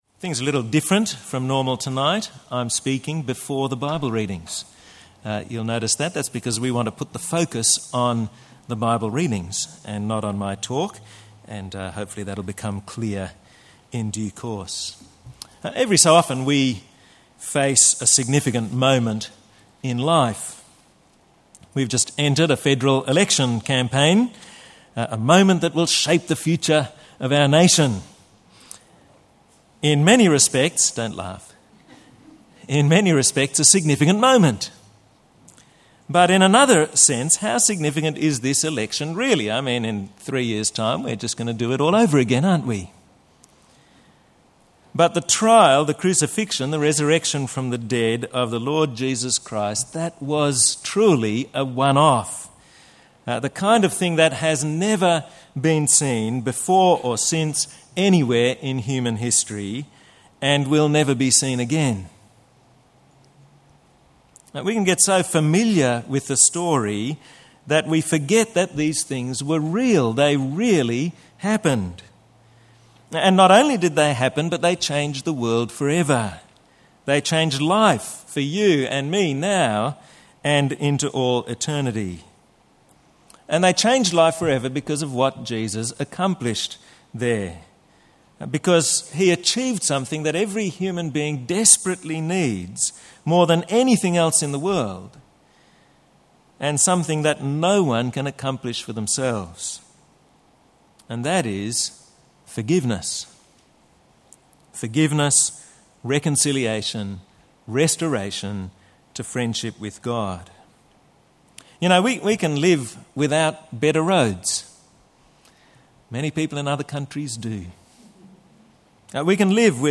Talk – How Can I be Sure?